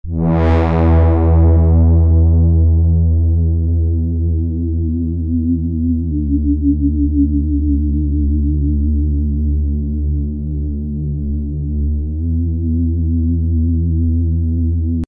Der Bass klingt mir noch etwas zu flach und nüchtern.
So klingt der Bass ohne diese Effekte: